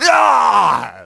pain-high-05.ogg